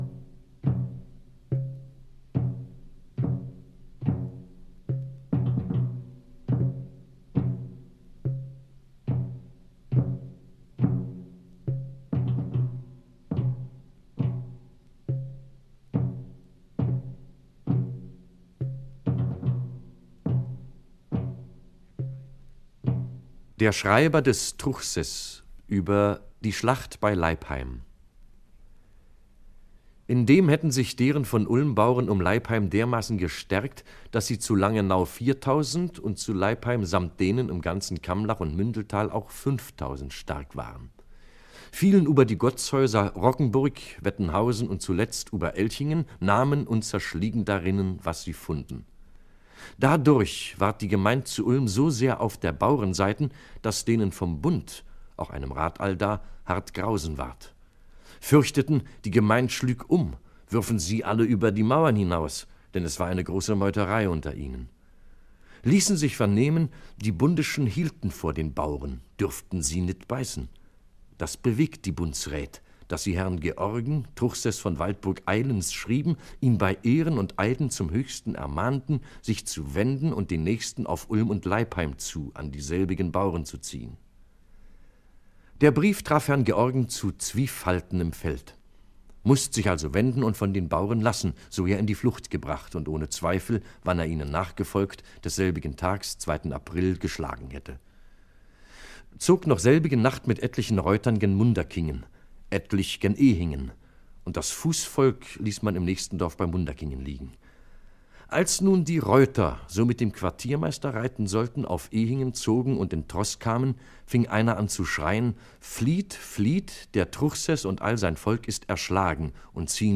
Hier der Text eingesprochen: Der Schreiber des Truchsess über die Schlacht bei Leipheim (LP Bauernkrieg 1525 – Dokumente 1, Litera 1974)